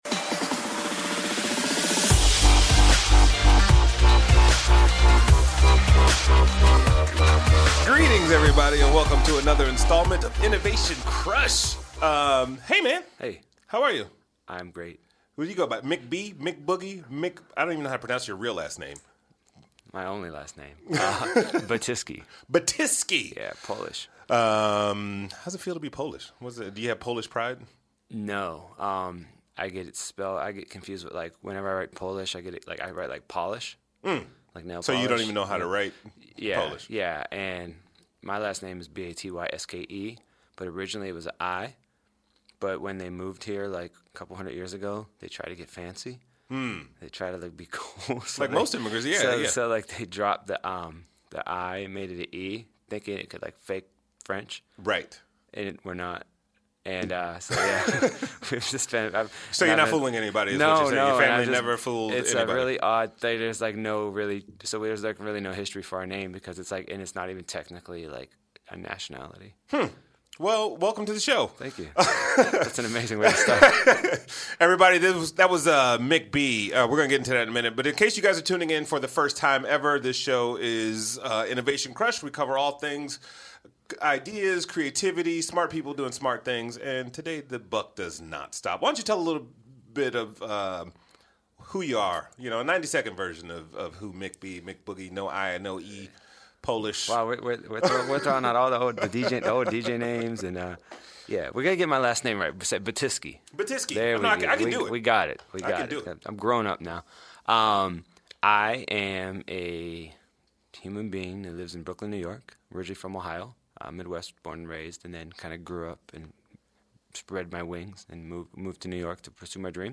Recorded live at the The SAE Institute.